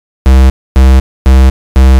TSNRG2 Off Bass 015.wav